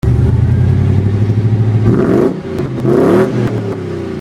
Sound Clips Needed! The "Official" Exhaust SoundClip Thread!
Twin 3" in/out 5x11x22 Magnaflows:
Magnaflow-Rev.mp3